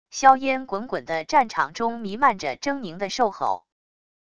硝烟滚滚的战场中弥漫着狰狞的兽吼wav音频